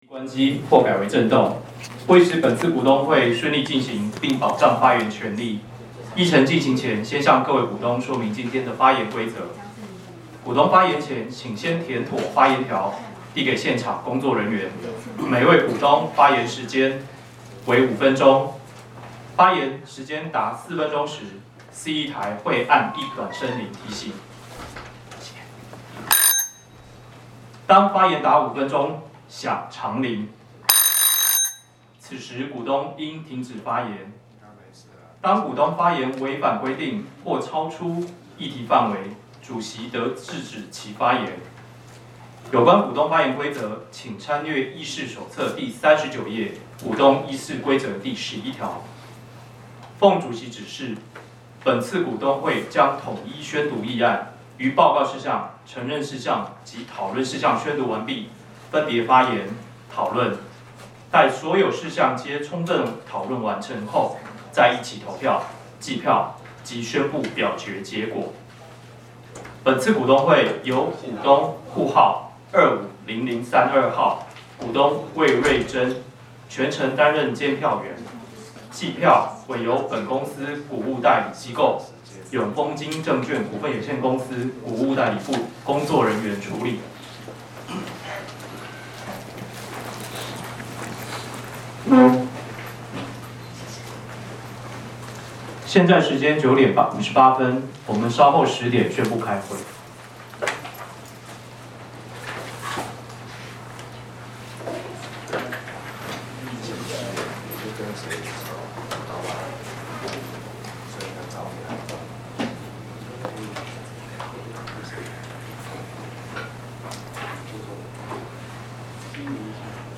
114年股東會錄音